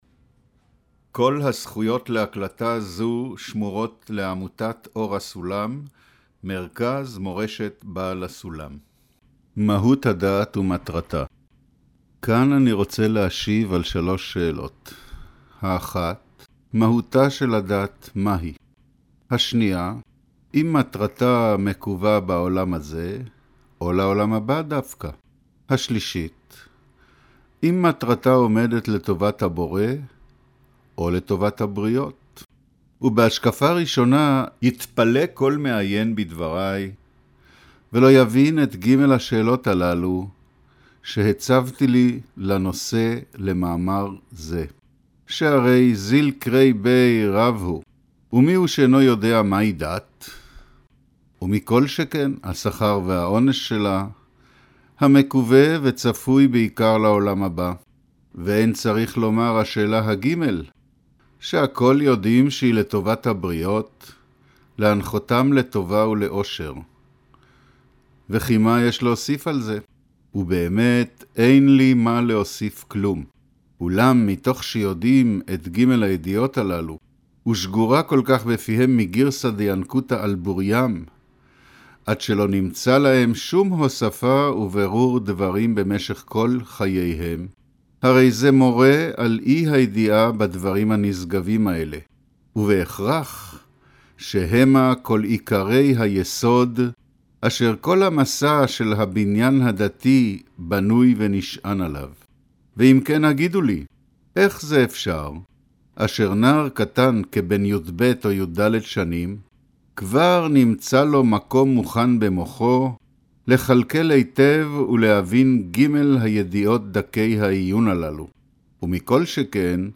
אודיו - קריינות מהות הדת ומטרתה